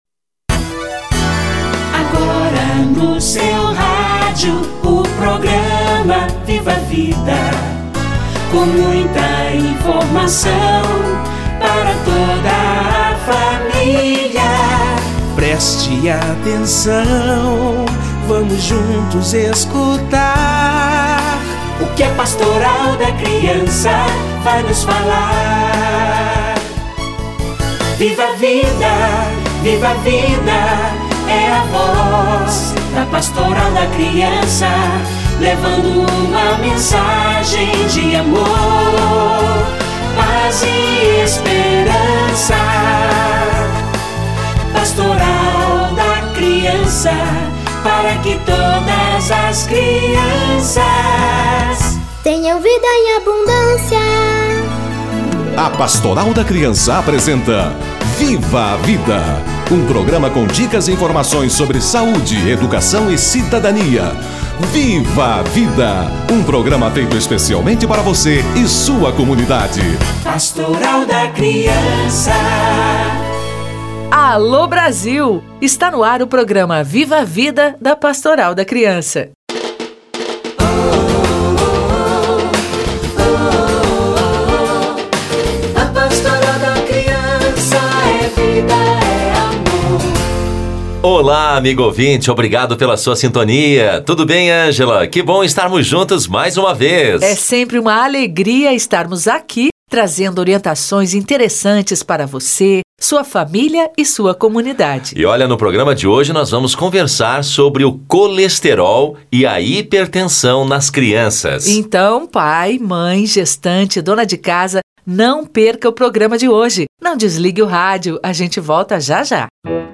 Colesterol e hipertensão na infância - Entrevista